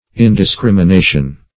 Indiscrimination \In`dis*crim`i*na"tion\, n.